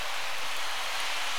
DoorsCrowd